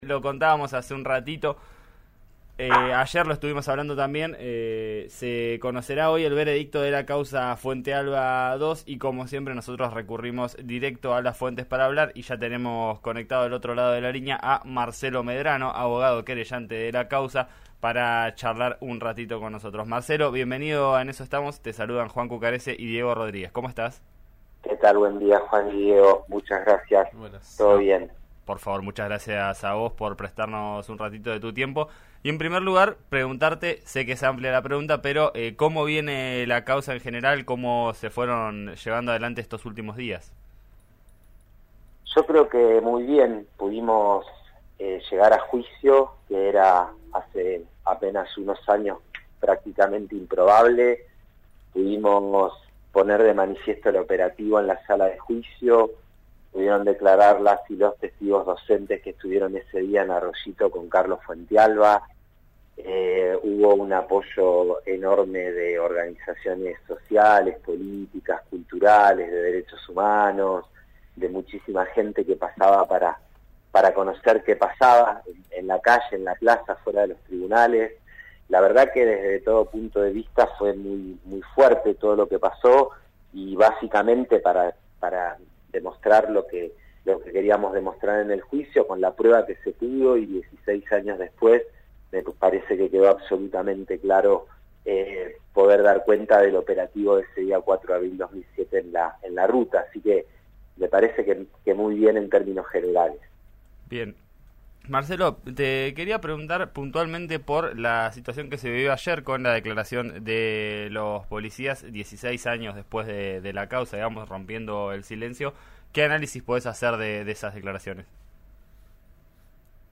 uno los abogados querellantes en RÍO NEGRO RADIO